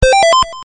このソフトに限らず、同社開発のSTGは全てポーズ音がコナミのそれと同一という謎仕様となっております。
このBGM・SEが使用されているタイトルをお答えください。